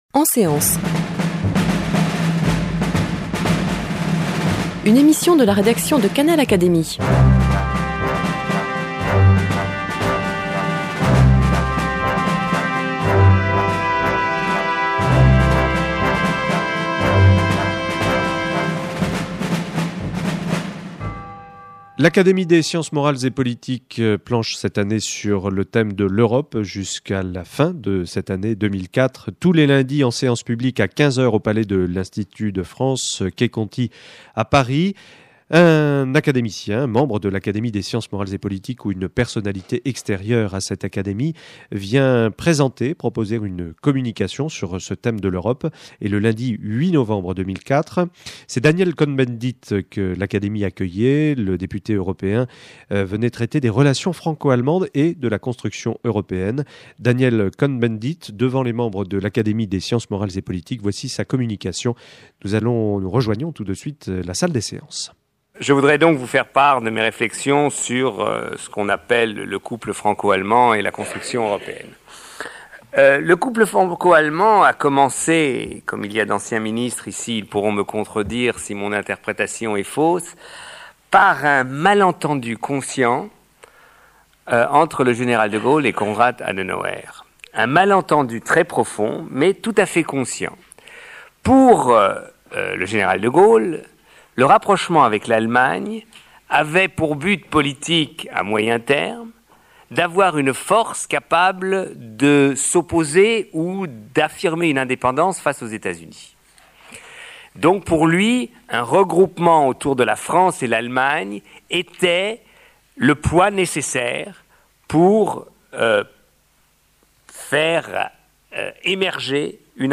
Communication de Daniel Cohn-Bendit prononcée en séance publique devant l’Académie des sciences morales et politiques le lundi 8 novembre 2004.